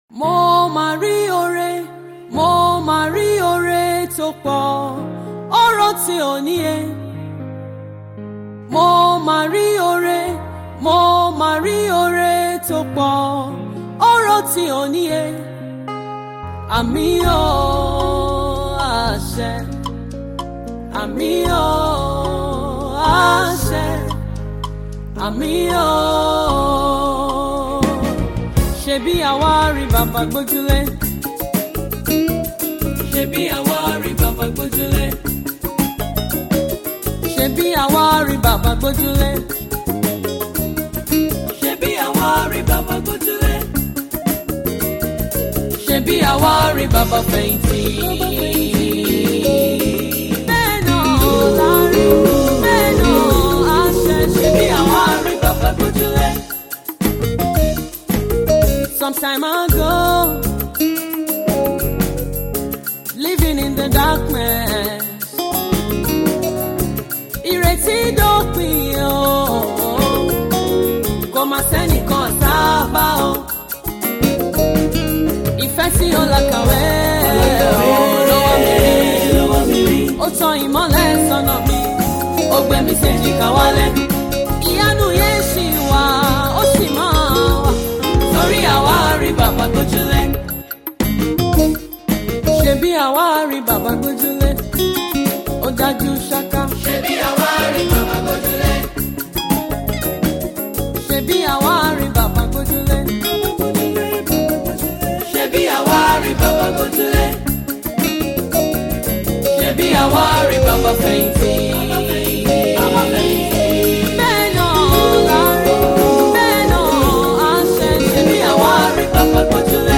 Nigerian gospel artist